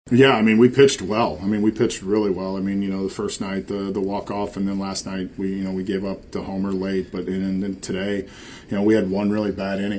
Shelton says his pitchers are doing their part.